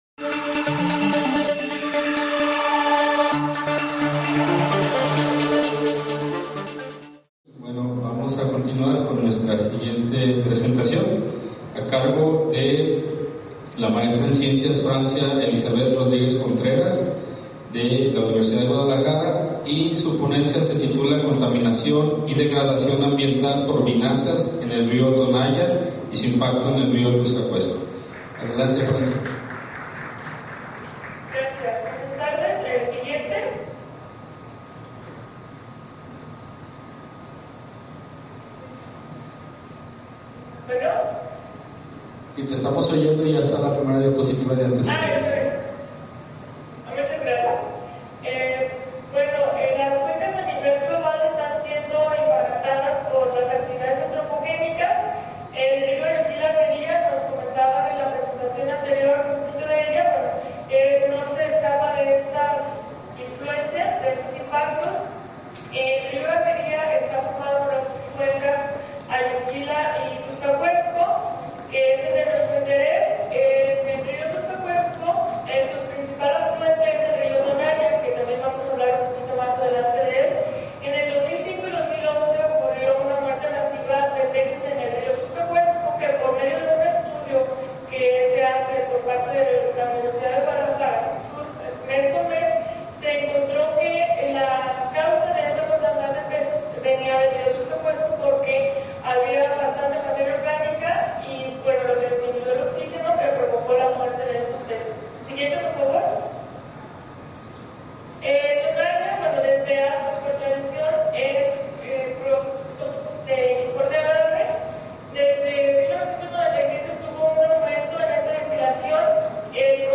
Audio de las conferencias